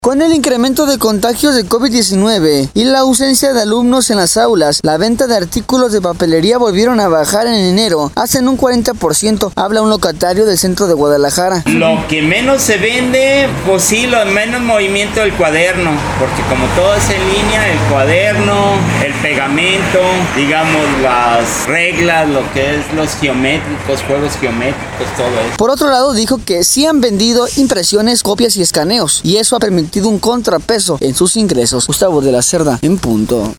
Con el incremento de contagios de Covid-19, y la ausencia de alumnos en las aulas, la venta de artículos de papelería volvieron a bajar en enero hasta en un 40%. Habla un locatario del centro de Guadalajara: